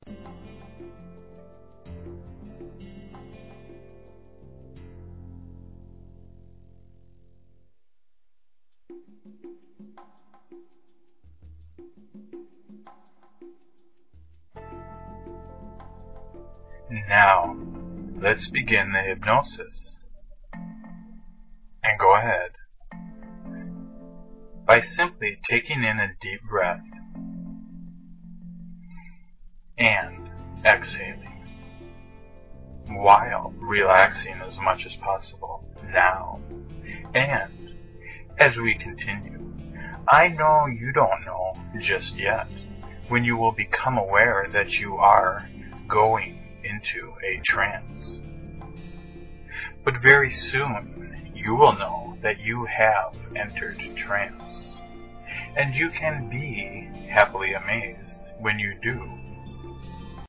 One Hour Self Confidence Hypnosis Audio mp3